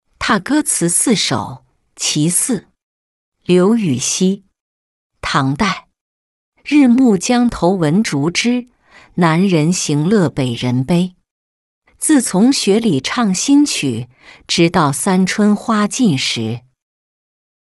踏歌词四首·其四-音频朗读